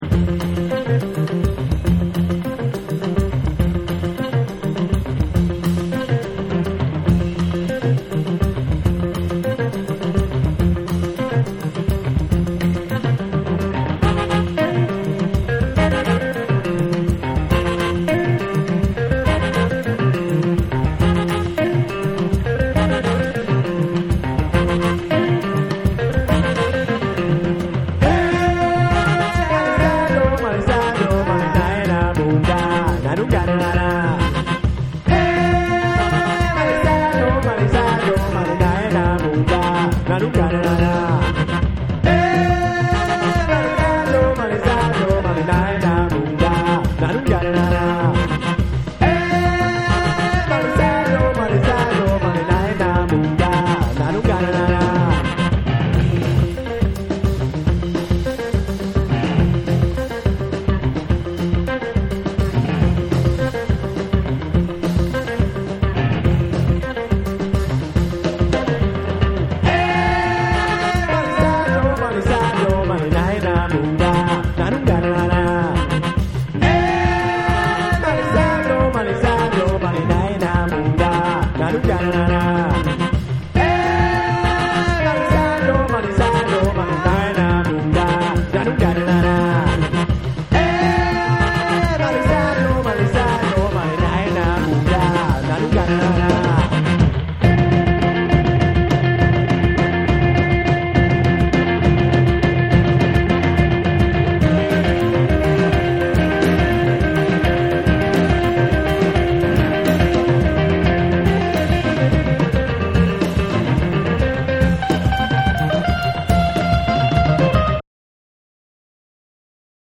format : 12inch